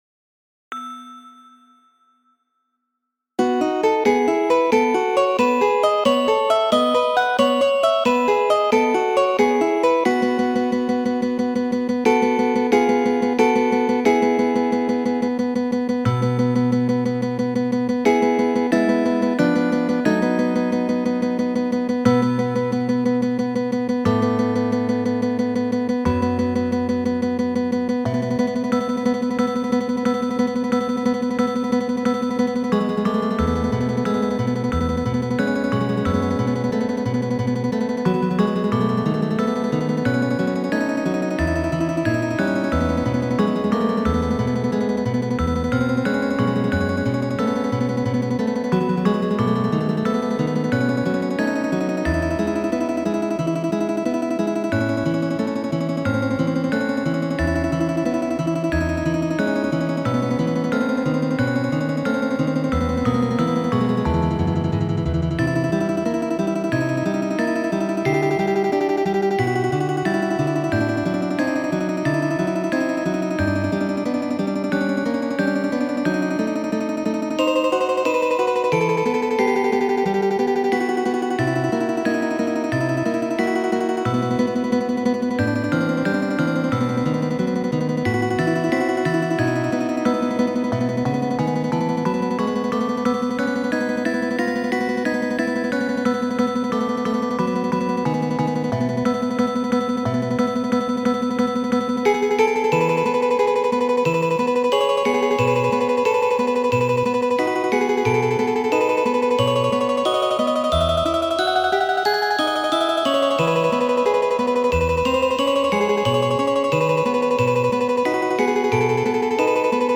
| Kajero 31 ª | En PDF (paĝo 26ª) | Kajeroj | Muziko : Tremolo estas tremolo de Francisko Tarrego sur origina temo de usona komponisto Ludoviko Moro Gottschalk . Jen la originala verko por piano.